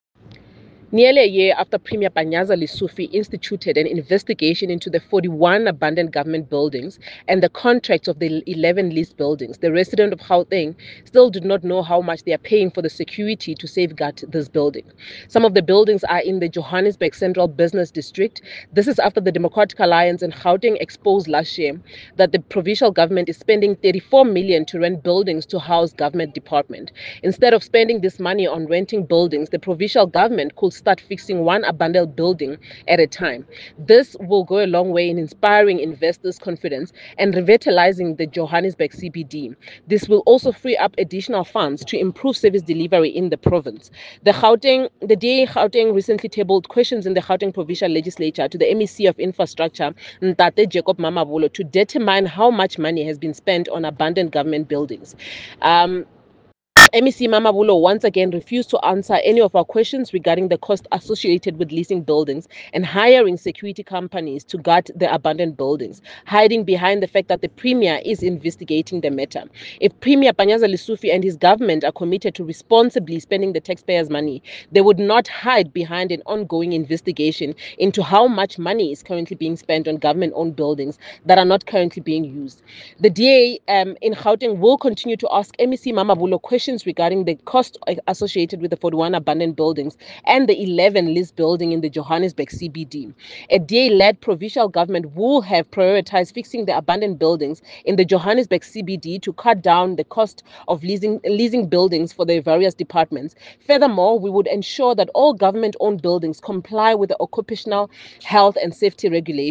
Note to Editors: Attached please find a soundbite in English by DA MPL, Khathutshelo Rasilingwane MPL